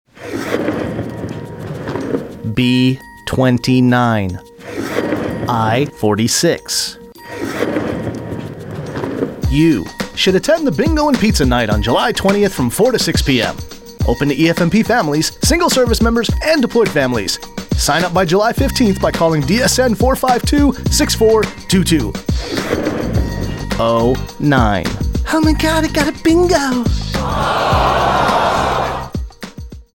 A 30 second spot for AFN Spangdahlem advertising a bingo and pizza night.